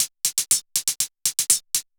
Index of /musicradar/ultimate-hihat-samples/120bpm
UHH_ElectroHatB_120-05.wav